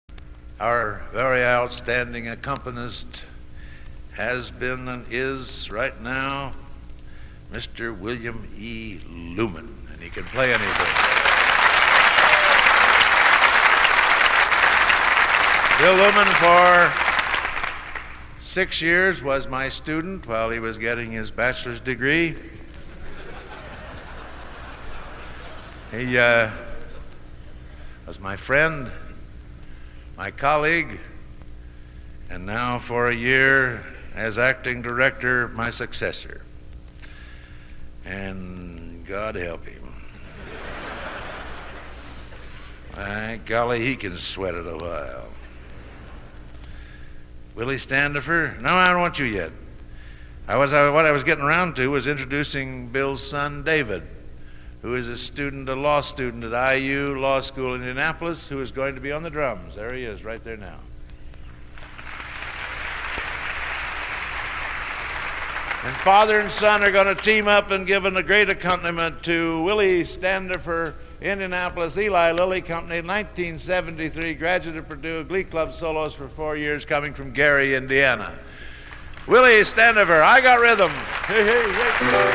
Genre: | Type: Director intros, emceeing